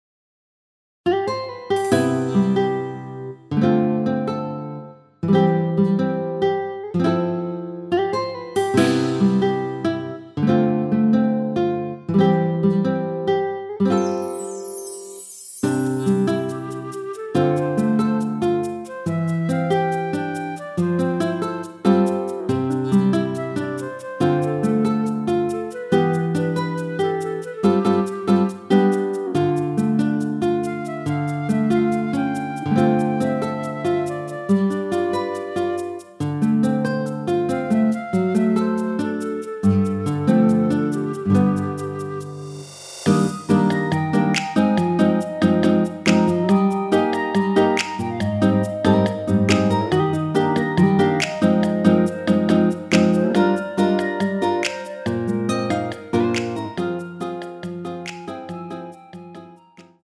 원키에서(+2)올린 멜로디 포함된 MR 입니다.(미리듣기 참조)
앞부분30초, 뒷부분30초씩 편집해서 올려 드리고 있습니다.
중간에 음이 끈어지고 다시 나오는 이유는